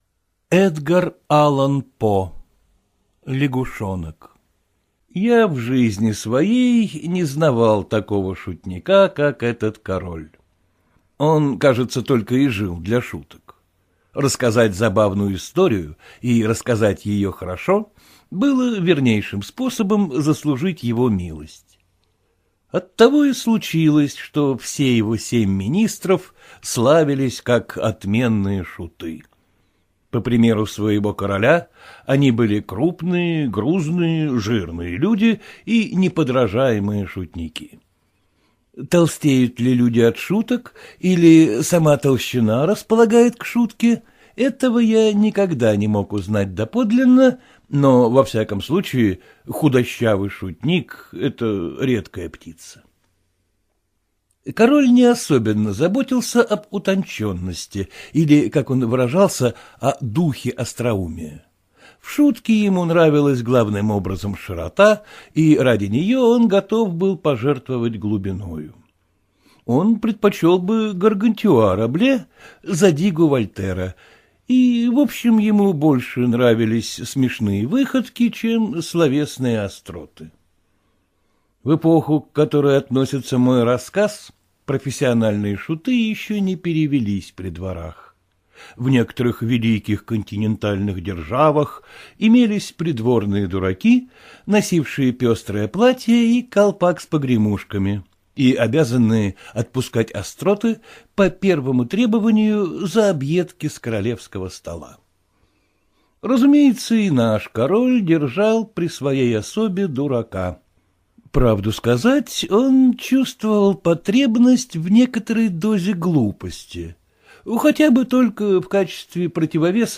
Лягушонок - аудио рассказ Эдгара По - слушать онлайн